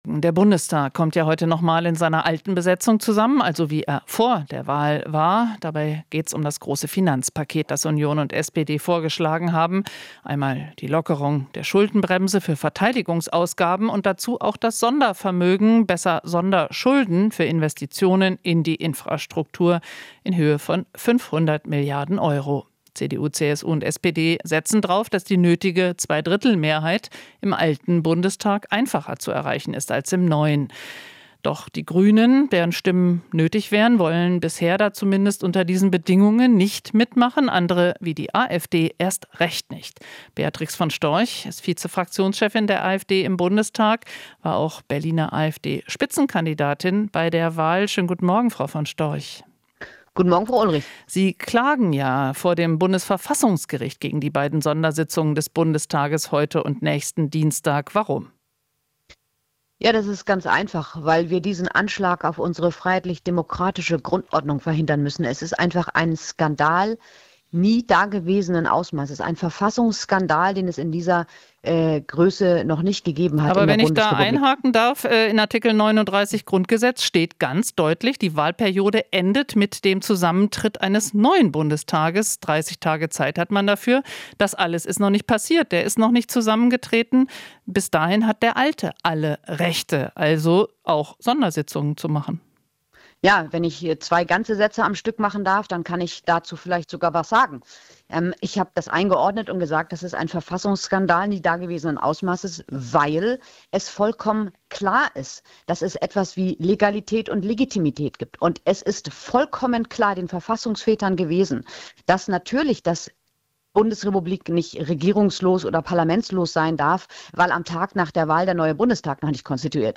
Interview - Von Storch (AfD): Union und SPD verachten den Wählerwillen